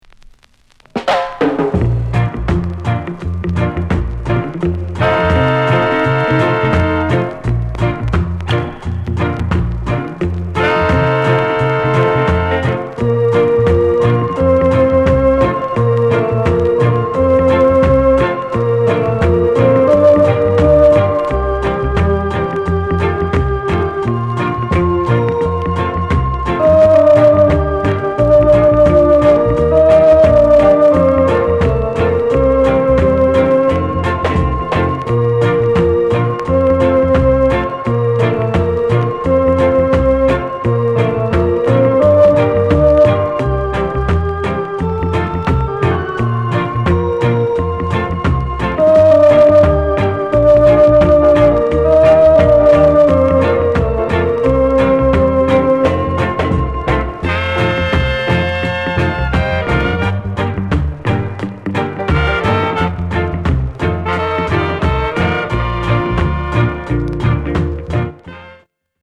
SOUND CONDITION A SIDE VG(OK)
ROCKSTEADY